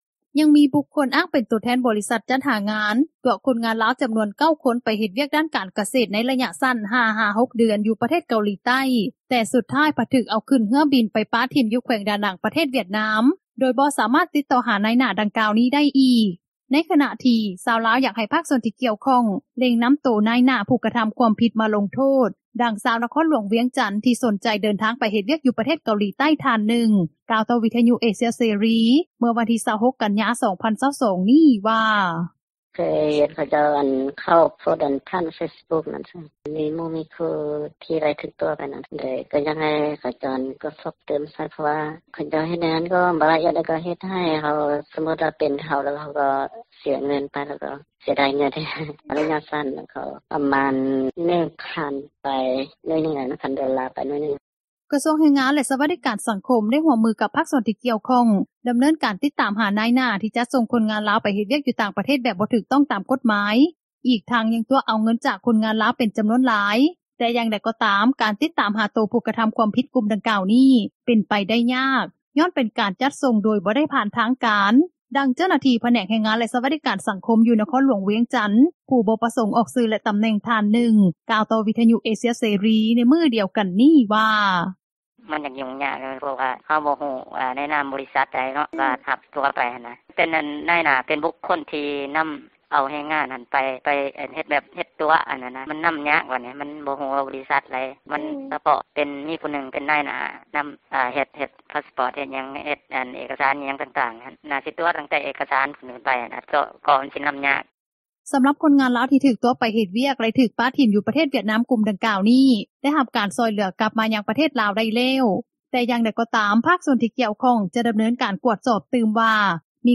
ດັ່ງເຈົ້າໜ້າທີ່ ທີ່ເຮັດວຽກກ່ຽວກັບການຈັດສົ່ງ ຄົນງານລາວ ໄປເຮັດວຽກຢູ່ຕ່າງປະເທດ ຜູ້ບໍ່ປະສົງອອກຊື່ ແລະຕໍາແໜ່ງນາງນຶ່ງ ກ່າວວ່າ:
ດັ່ງຊາວນະຄອນຫຼວງວຽງຈັນ ທີ່ສົນໃຈເດີນທາງ ໄປເຮັດວຽກຕາມຣະດູການ ຢູ່ປະເທດເກົາຫຼີໃຕ້ ກ່າວວ່າ: